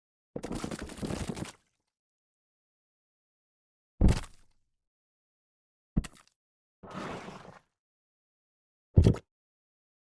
Free SFX sound effect: Single Mud Hits.
Single Mud Hits
yt_xyAmQU1xBao_single_mud_hits.mp3